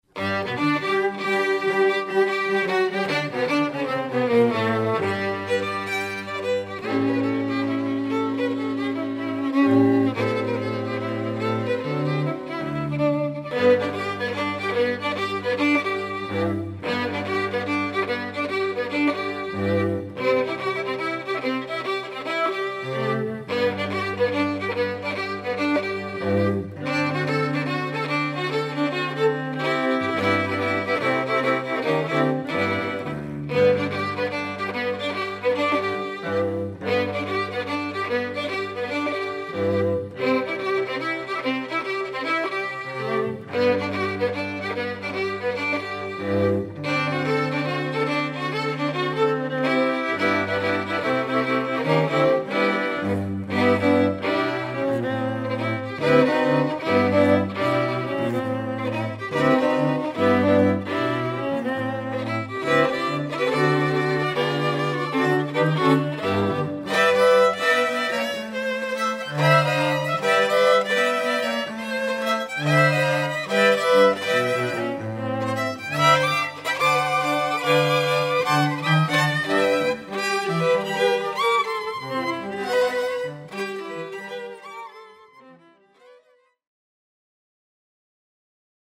(Two Violins, Viola, & Cello)